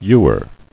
[ew·er]